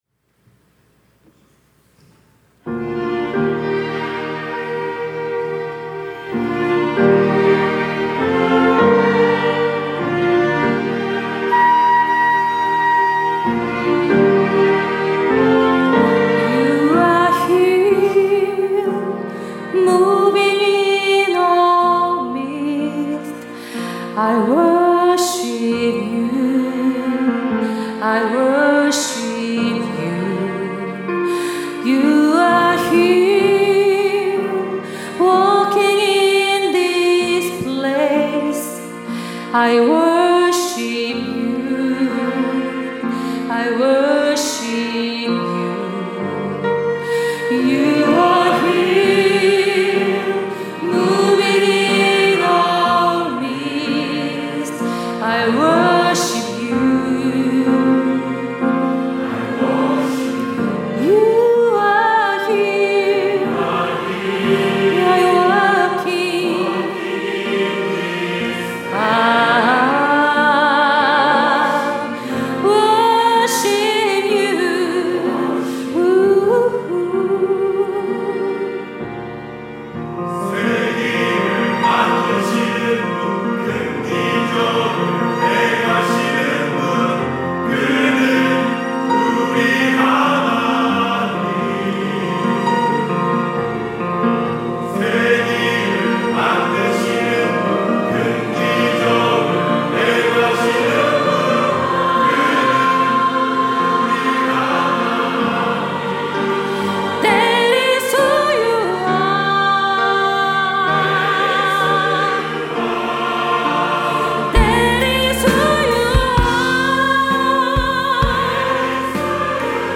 특송과 특주 - 길을 만드시는 주 (Way Maker)